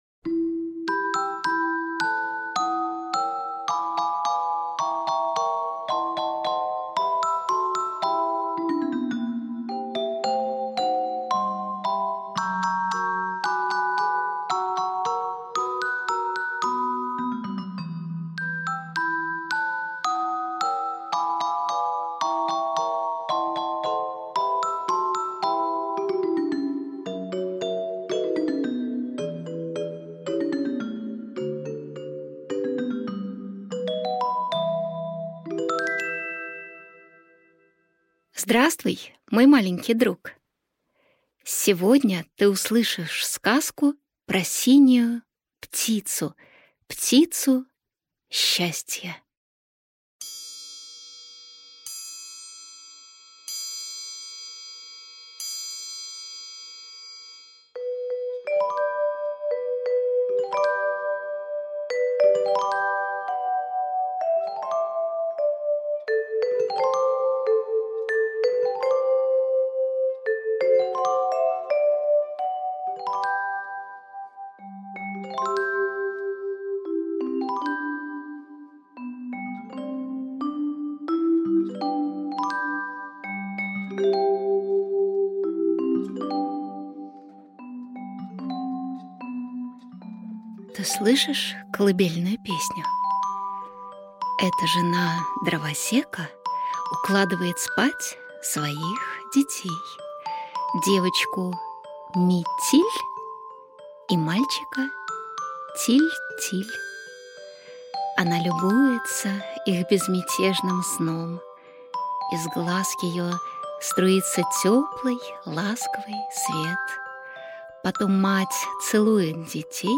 Аудиокнига Синяя птица. Сказка-путешествие.